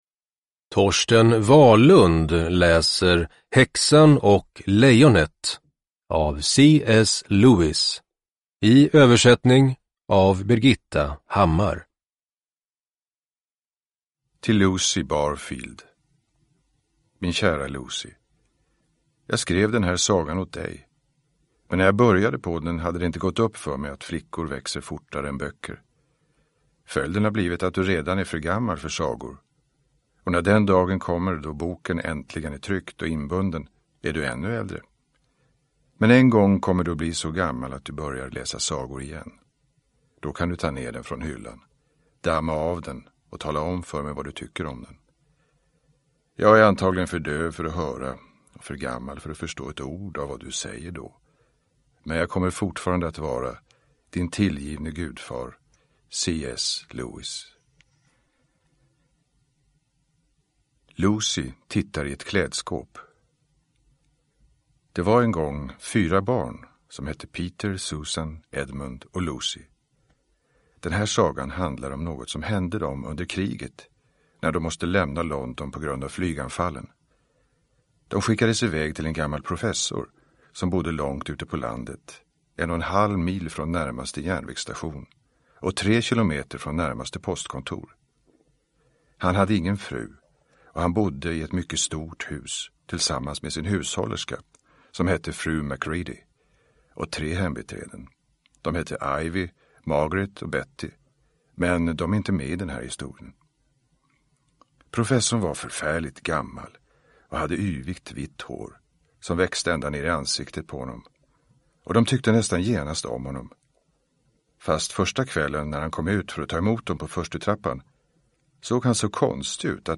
Häxan och lejonet – Ljudbok – Laddas ner
Uppläsare: Torsten Wahlund